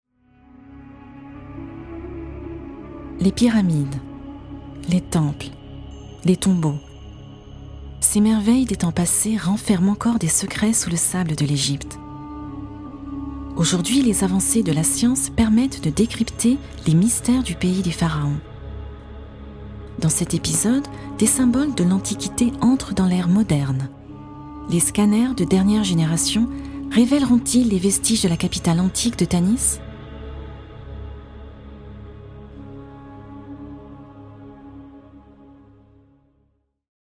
Audioguide en 4 langues : français/anglais/allemand/espagnol